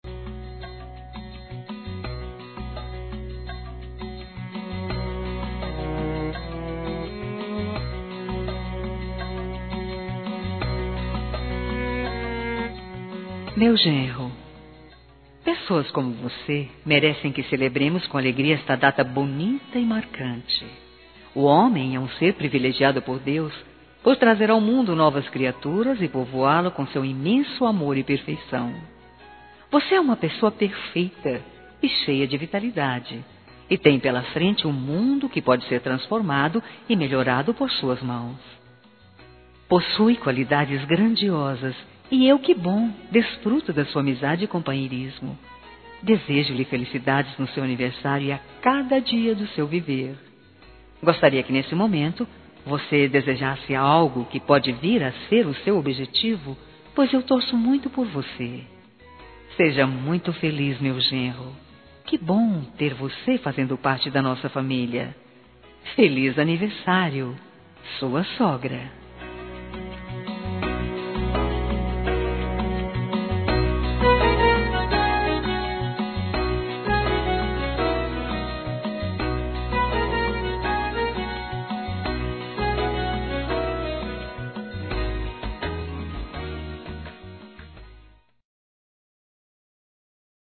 Aniversário de Genro – Voz Feminina – Cód: 2427